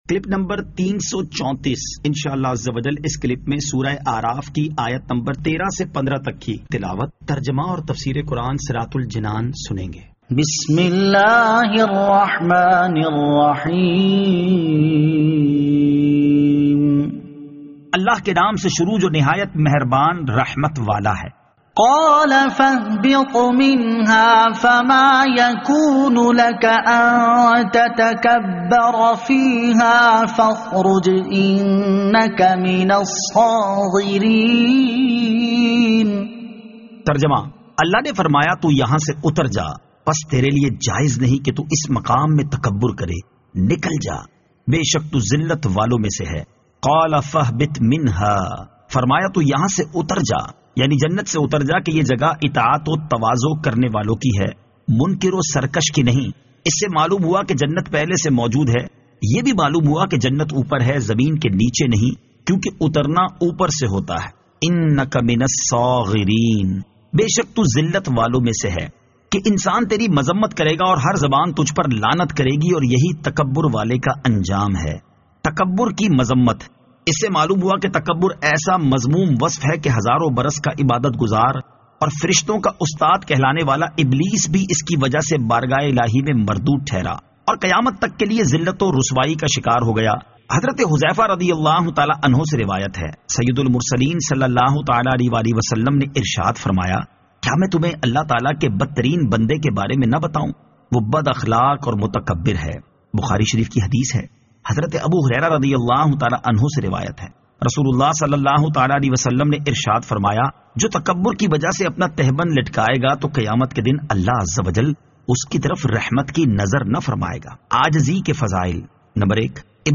Surah Al-A'raf Ayat 13 To 15 Tilawat , Tarjama , Tafseer